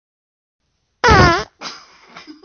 真实的屁 " 屁13
描述：真屁
Tag: 现实 放屁 真正